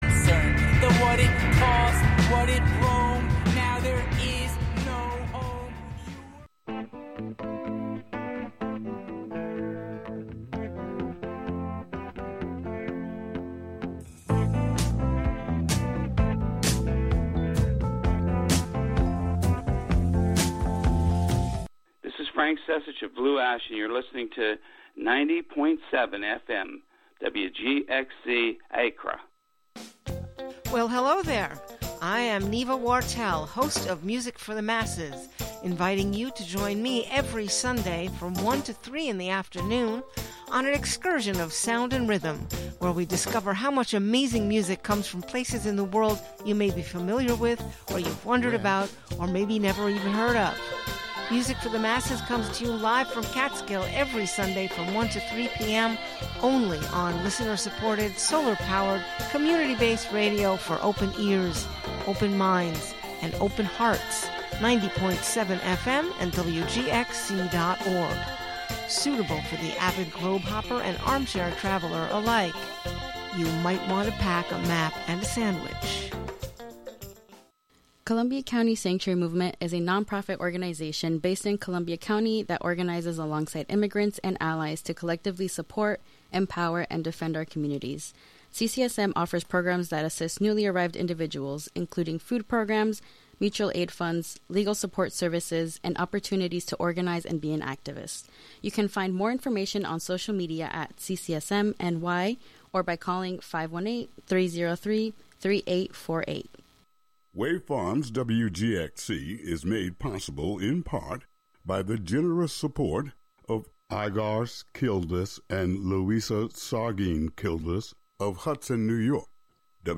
Tune in on Friday nights from 8 p.m. to midnight for music and sounds from artists and musicians living in the Hudson Valley, Capital District, and Western Massachusetts.